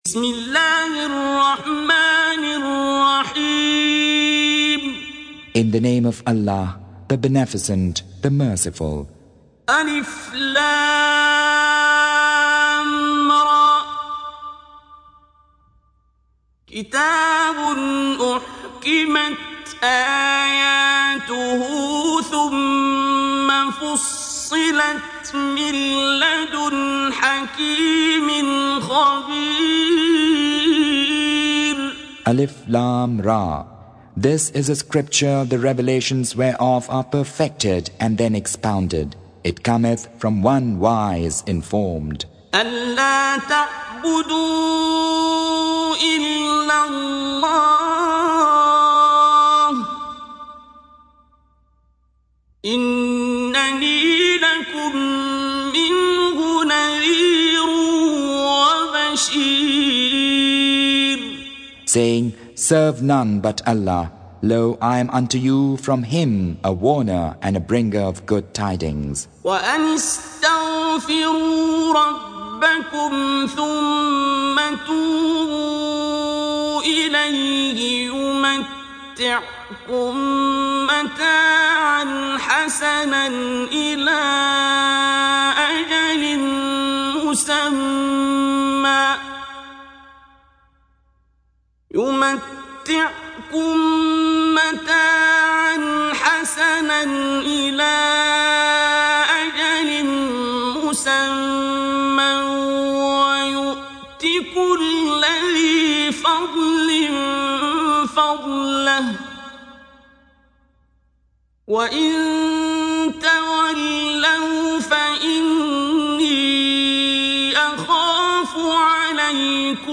Surah Sequence تتابع السورة Download Surah حمّل السورة Reciting Mutarjamah Translation Audio for 11. Surah H�d سورة هود N.B *Surah Includes Al-Basmalah Reciters Sequents تتابع التلاوات Reciters Repeats تكرار التلاوات